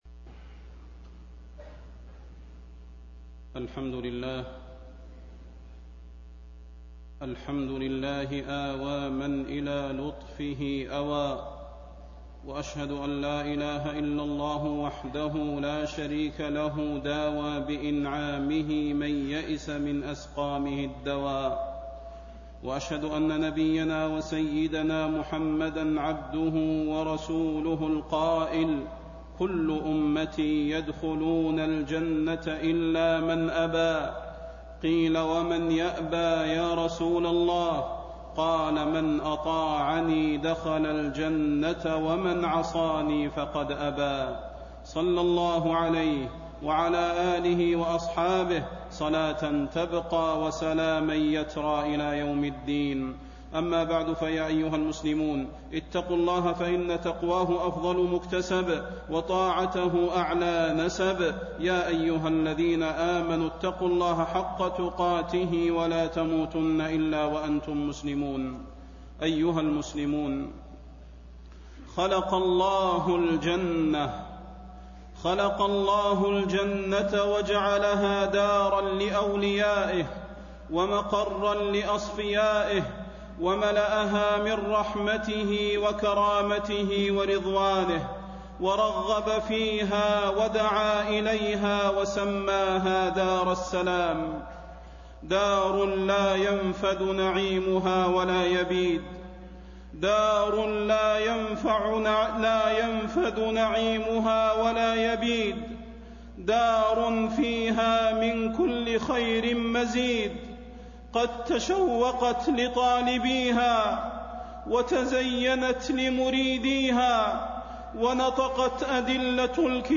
تاريخ النشر ٥ ربيع الثاني ١٤٣٤ هـ المكان: المسجد النبوي الشيخ: فضيلة الشيخ د. صلاح بن محمد البدير فضيلة الشيخ د. صلاح بن محمد البدير الجنة ونعيمها